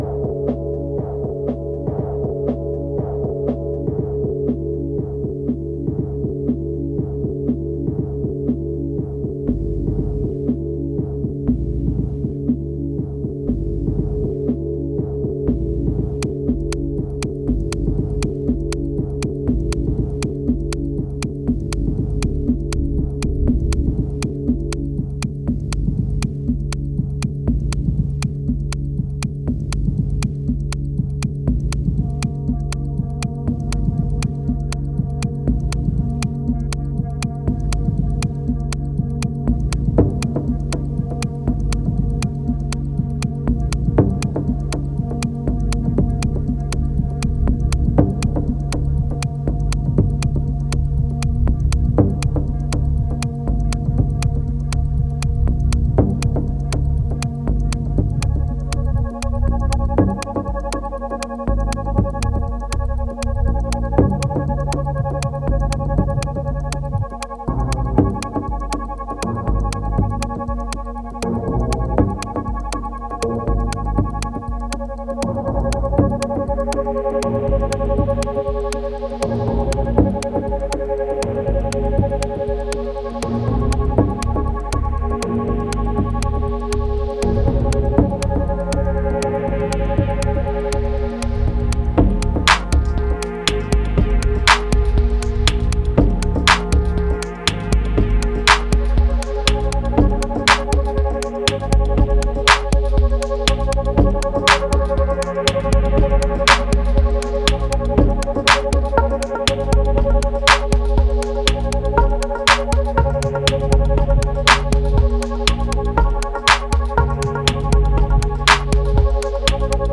3211📈 - 29%🤔 - 120BPM🔊 - 2016-04-05📅 - -114🌟